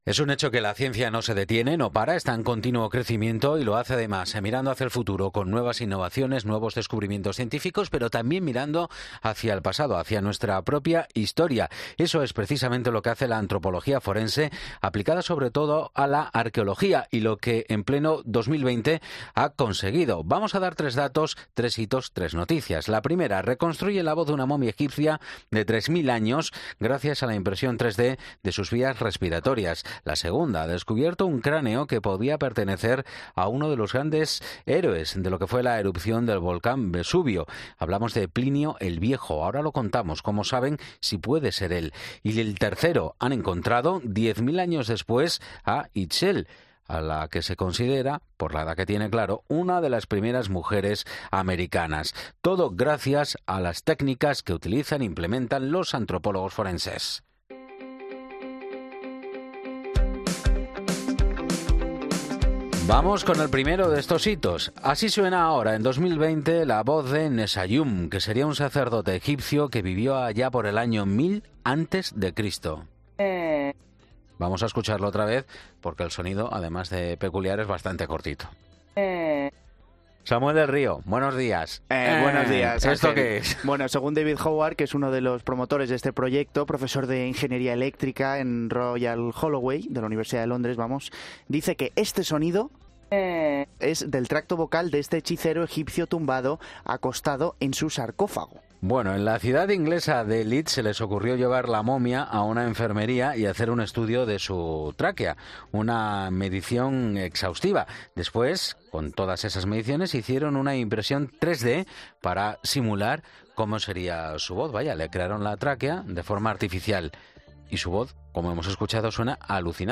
Recrean 3000 años después la voz de un sacerdote egipcio momificado